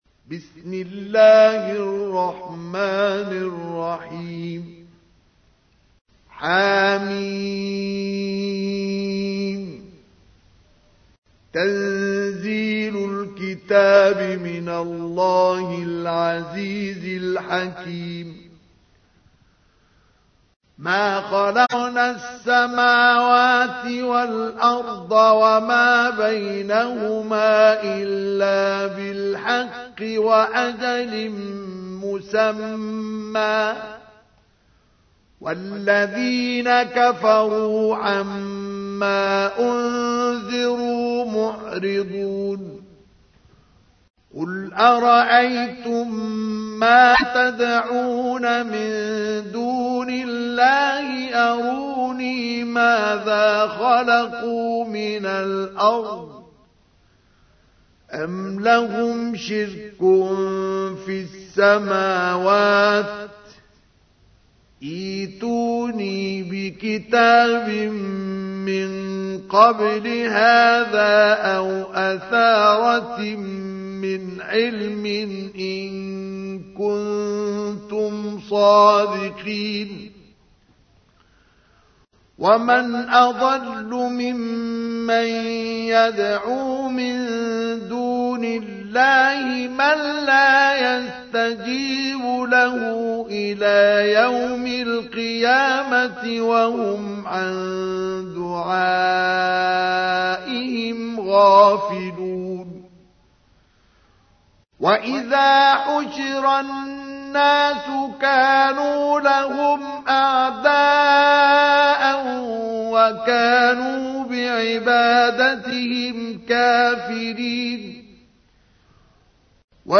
تحميل : 46. سورة الأحقاف / القارئ مصطفى اسماعيل / القرآن الكريم / موقع يا حسين